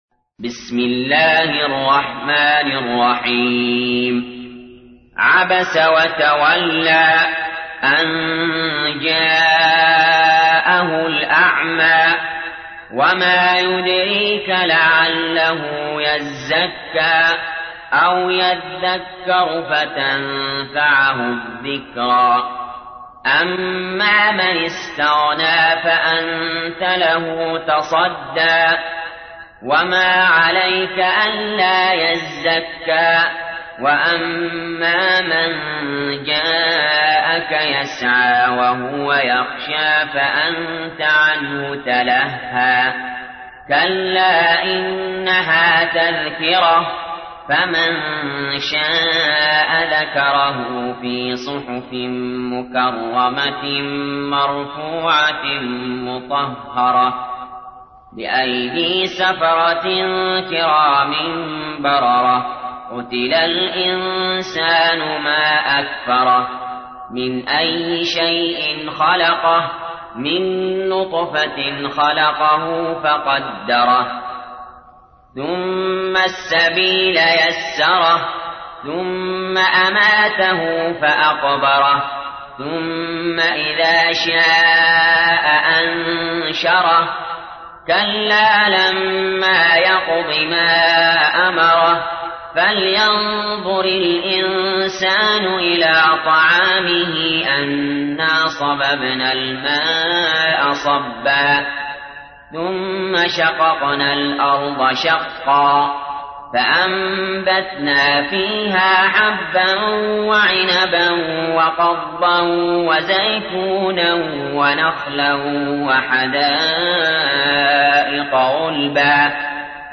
تحميل : 80. سورة عبس / القارئ علي جابر / القرآن الكريم / موقع يا حسين